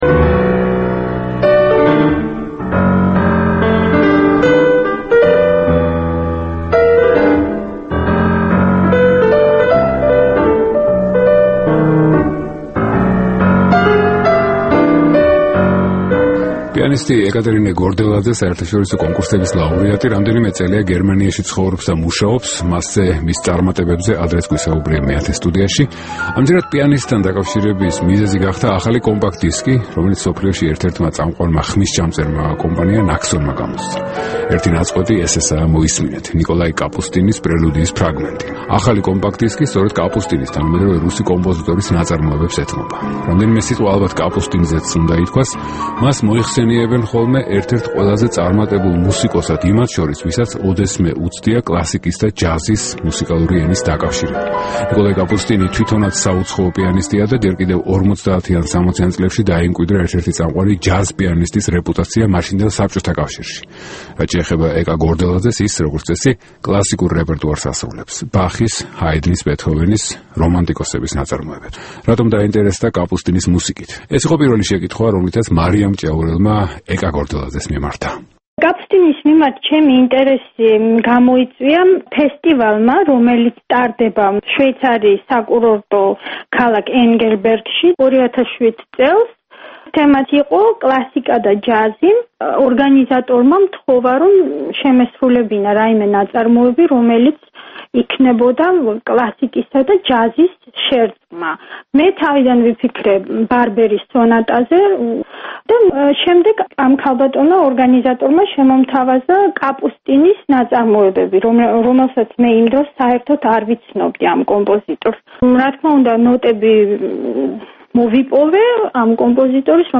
კლასიკისა და ჯაზის შერწყმა - ქართველი პიანისტის შესრულებით